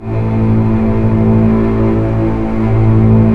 Index of /90_sSampleCDs/Roland L-CD702/VOL-1/STR_Cbs Arco/STR_Cbs2 Orchest